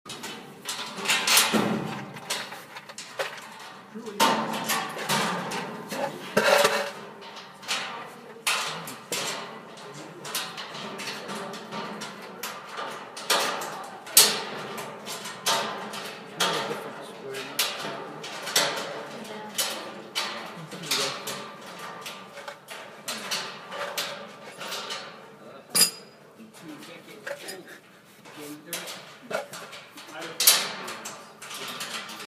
On the grid during 55 Dinging and banging sounds, screwing nuts and bolts into the holes of the shelves, mumbling, the cup of bolts shaking, a wrench hitting the floor.
Building-A-Shelf.mp3